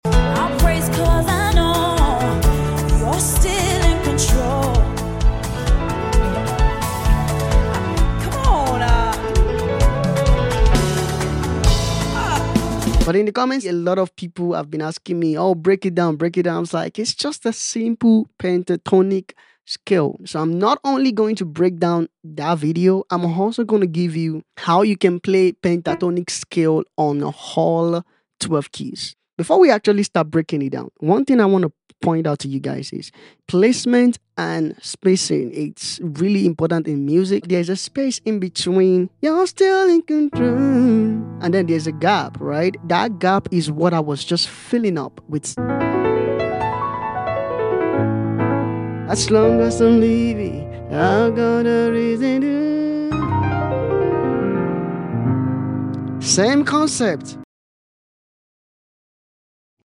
Play pentatonic scale on all keys.